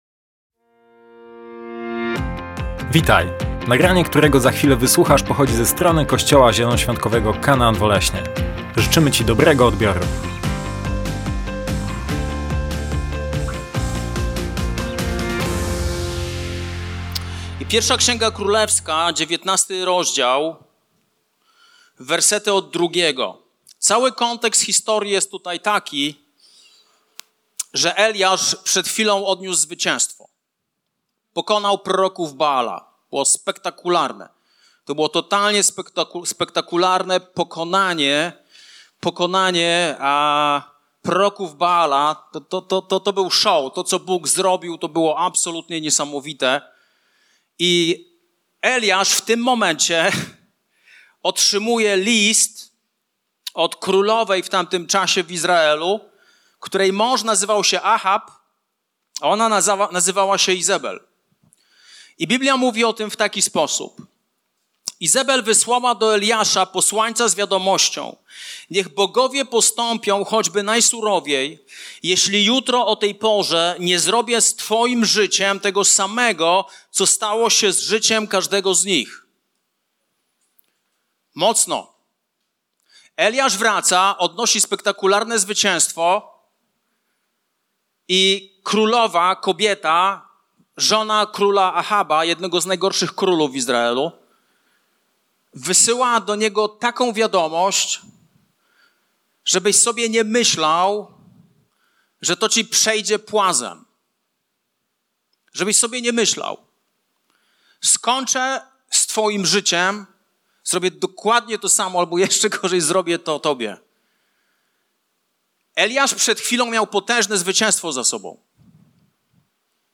Kazania - KANAAN OLESNO